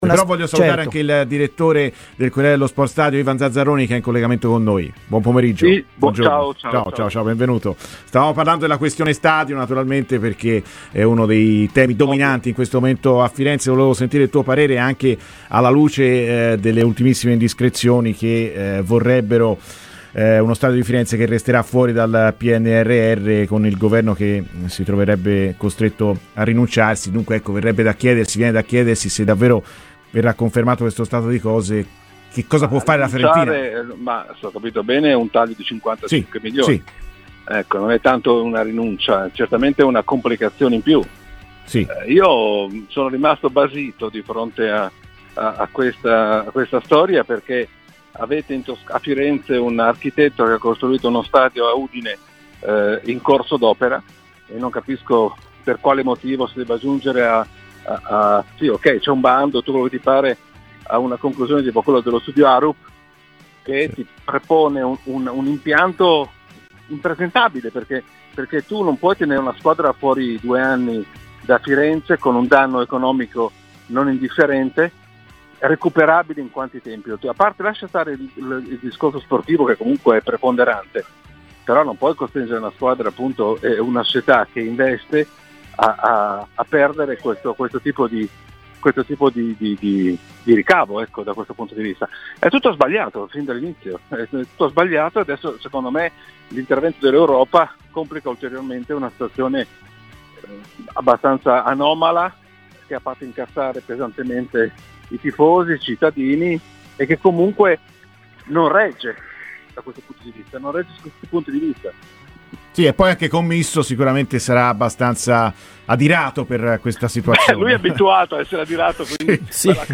Ha parlato ai microfoni di Radio FirenzeViola Ivan Zazzaroni, direttore del Corriere dello Sport - Stadio, iniziando proprio dal tema stadio "Io sono rimasto basito di fronte a questa storia.